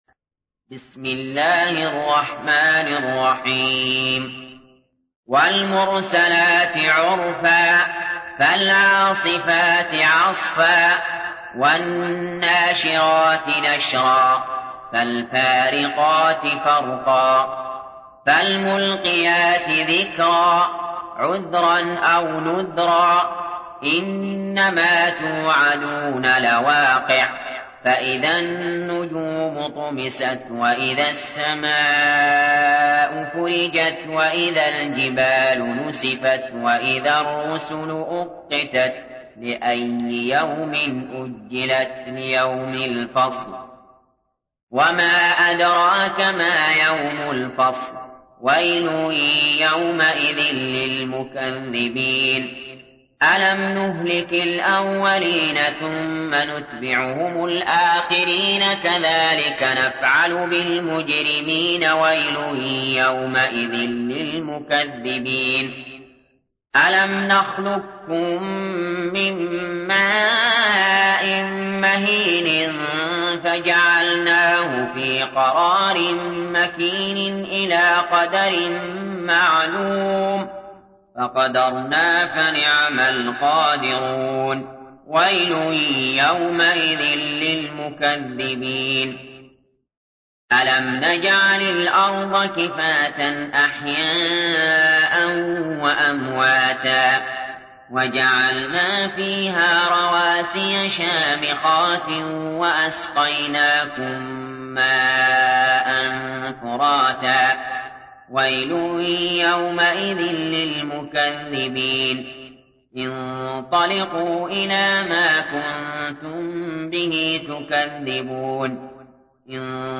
تحميل : 77. سورة المرسلات / القارئ علي جابر / القرآن الكريم / موقع يا حسين